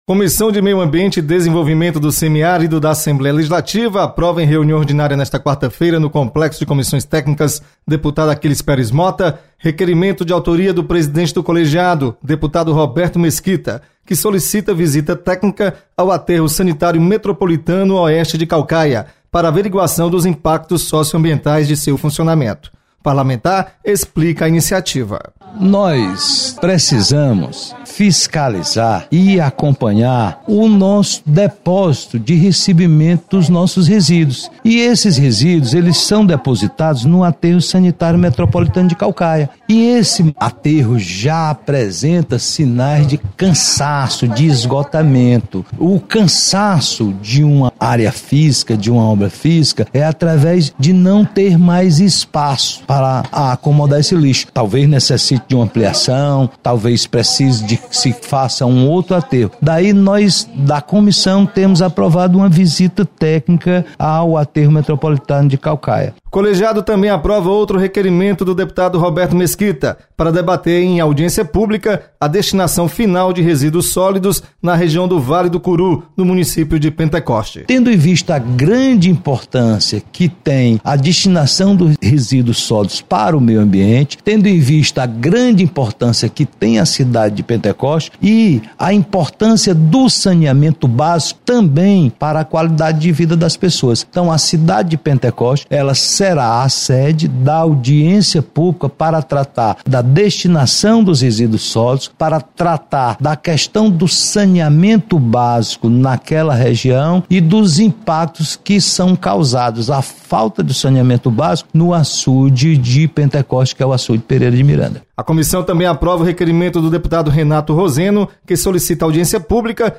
Comissão de Meio Ambiente aprova visita técnica ao aterro  sanitário de Caucaia. Repórter